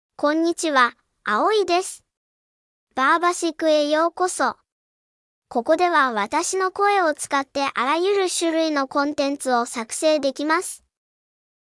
Aoi — Female Japanese (Japan) AI Voice | TTS, Voice Cloning & Video | Verbatik AI
Aoi is a female AI voice for Japanese (Japan).
Voice sample
Listen to Aoi's female Japanese voice.
Female
Aoi delivers clear pronunciation with authentic Japan Japanese intonation, making your content sound professionally produced.